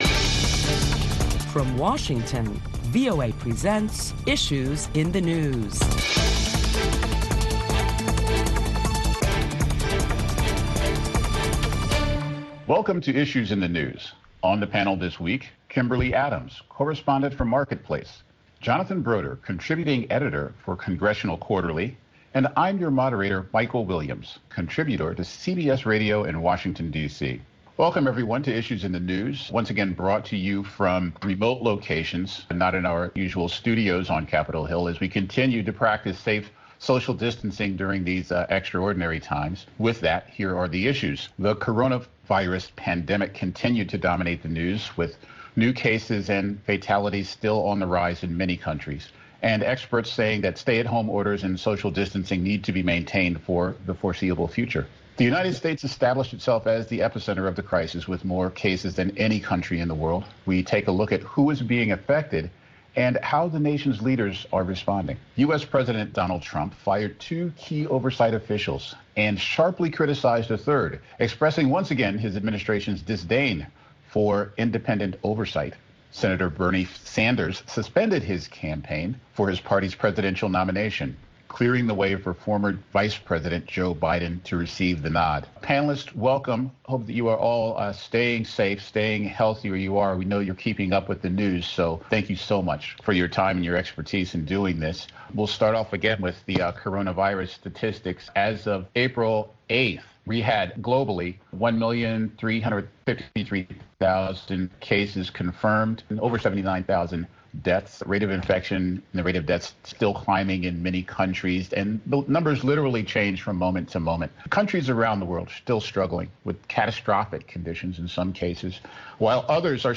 Listen to a panel of prominent Washington journalists as they deliberate the latest top stories of the week that include the economic ramifications of COVID-19.